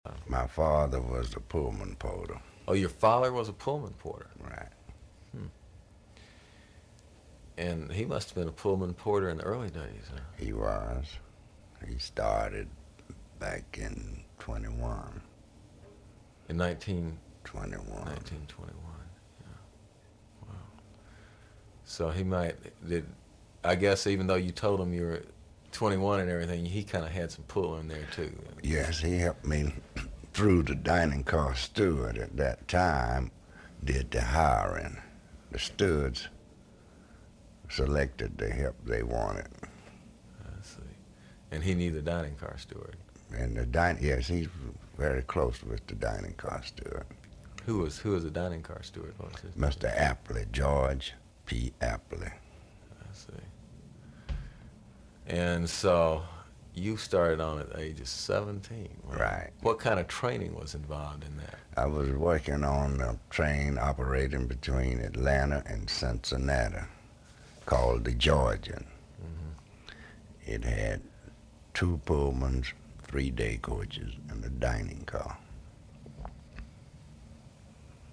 Radio Free Georgia has even partnered with Atlanta History Center to record a fascinating series of oral histories that detail the experiences of Atlantans great and small between the first and second World Wars.